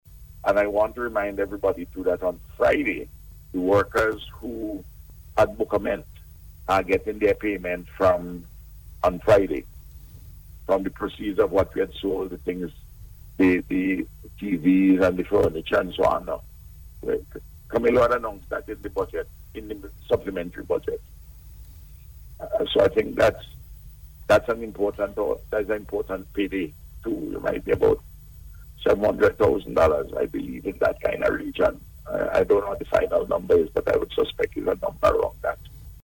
Prime Minister Dr. Ralph Gonsalves said on NBC Radio on Monday that the auction sale of the contents of the Resort generated over 700-thousand EC dollars.